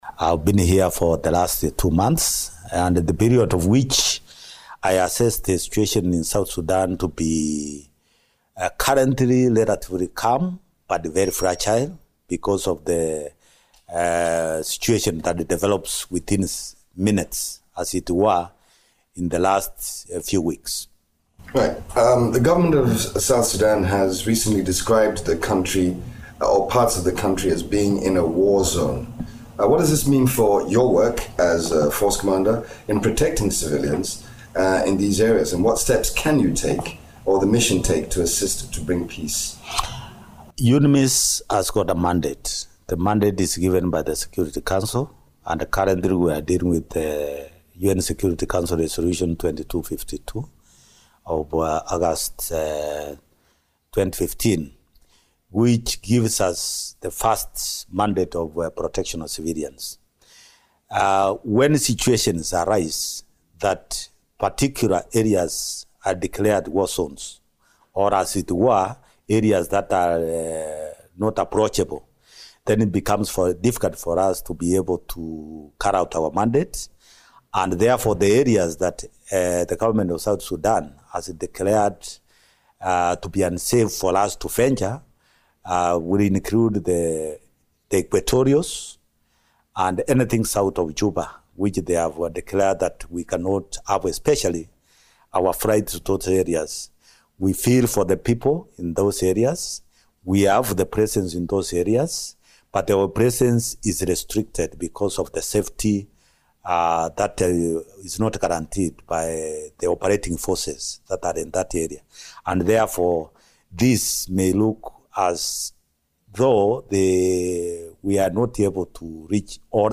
The force commander spoke to Radio Miraya in this exclusive interview